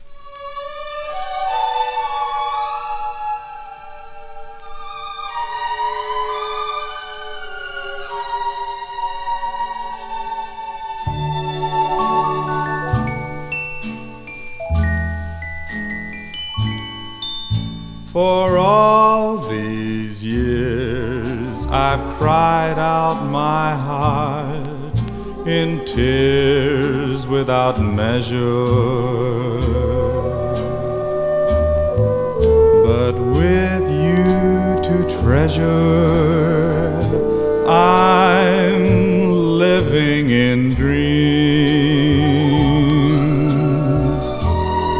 通して、芳醇で柔らかく滑らかで遊び心も織り交ぜた溜息漏れそう脱力するよな傑作です。
Jazz　USA　12inchレコード　33rpm　Mono